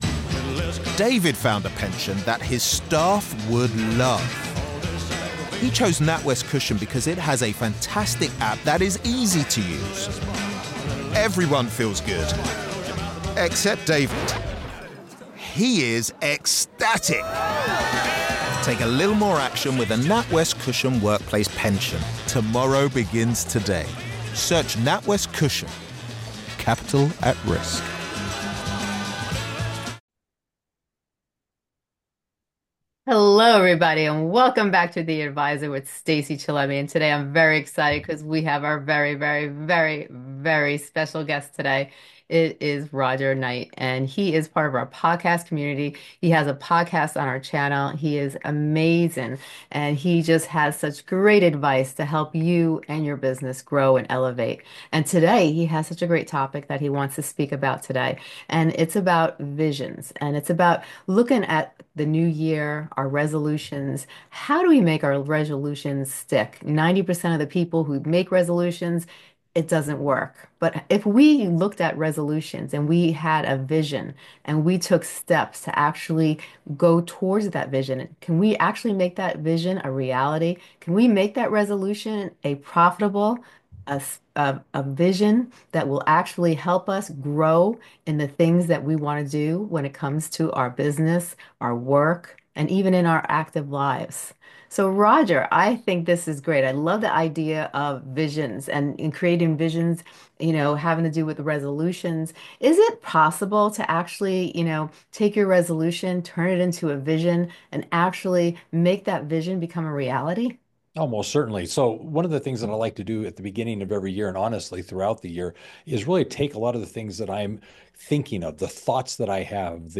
Tune in to gain insights, inspiration, and actionable strategies to create a compelling vision for your future. Don't miss out on this empowering conversation that will ignite your passion and drive!